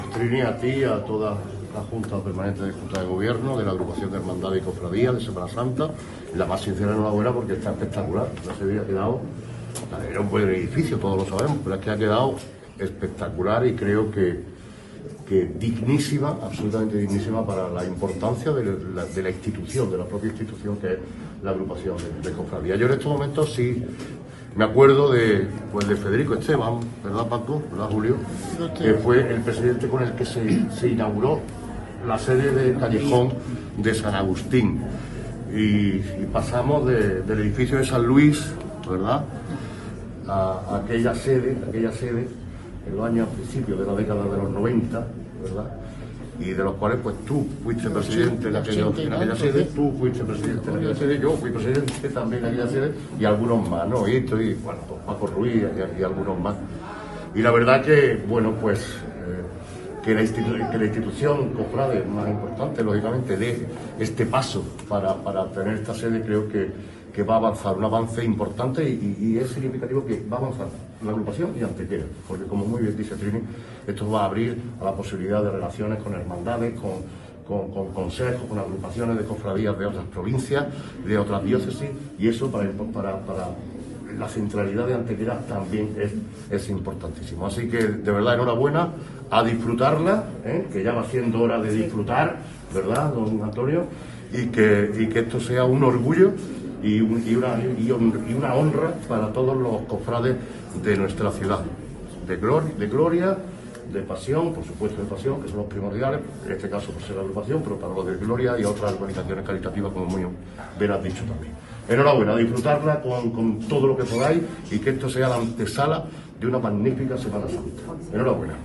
El alcalde de Antequera, Manolo Barón, destacaba durante el acto inaugural que estas nuevas instalaciones vienen a ser “una digna sede para la importancia de una institución como es la Agrupación”, Medalla de Oro de la Ciudad e Institución Predilecta.
Cortes de voz